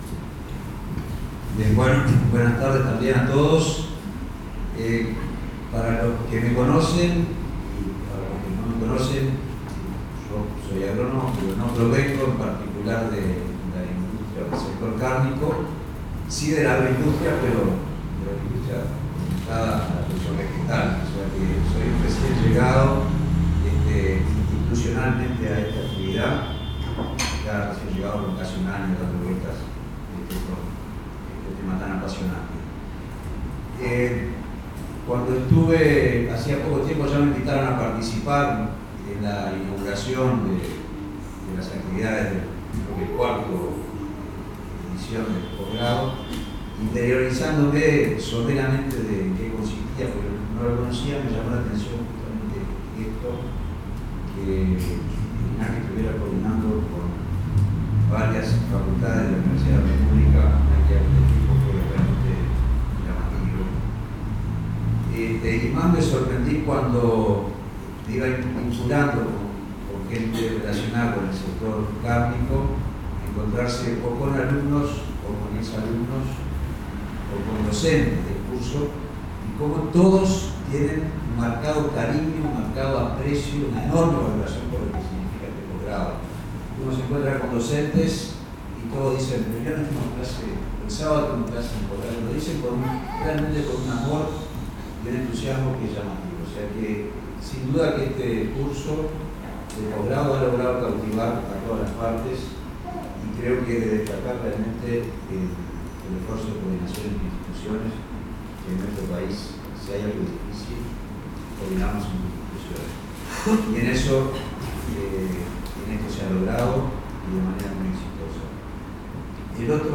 Entrega de Títulos de 3° Edición de Posgrado Especialización en Industria Cárnica.
El Presidente de INAC Federico Stanham subrayó además la relevancia de esta Especialización en un país ganadero como el nuestro.
Presidente de INAC.MP3